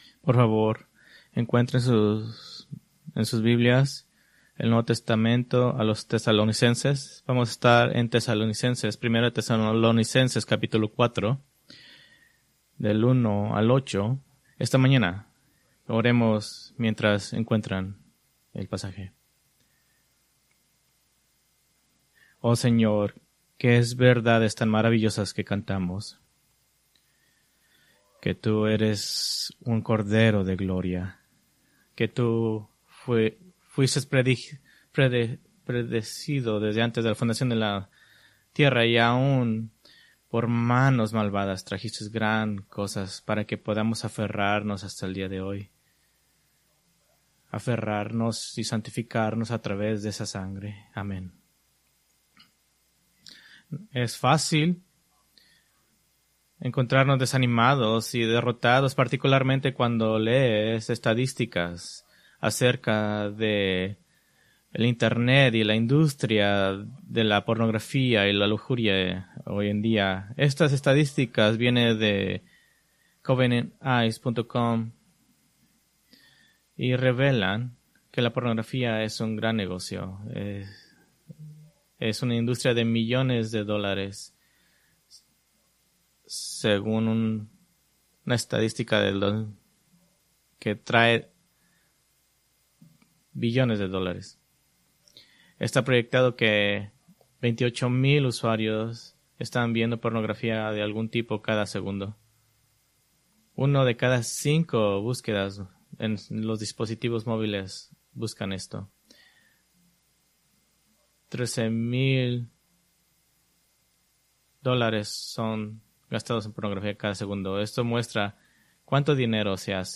Preached October 27, 2024 from 1 Thessalonians 4:1-8